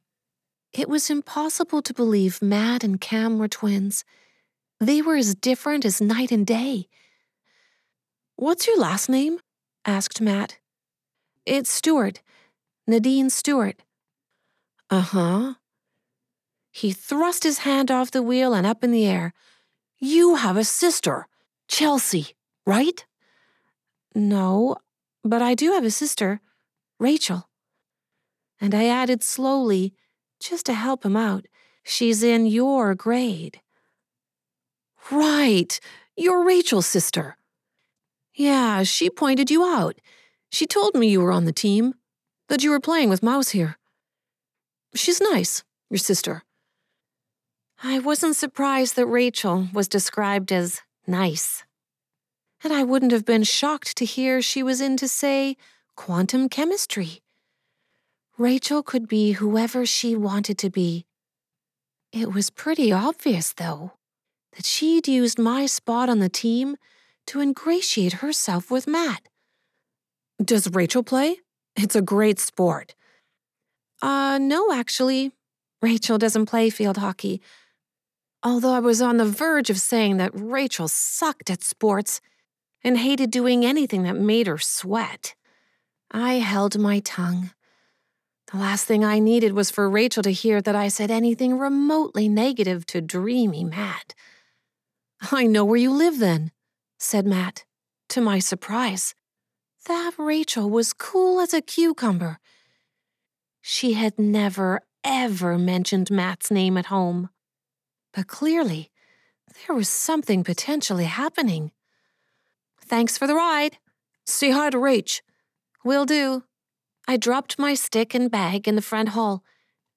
NemeSIS audiobook release December 2021!